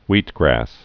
(wētgrăs, hwēt-)